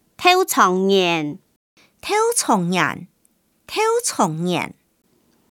Hakka tts 用中文字典方式去mapping客語語音 客語語音來源 1.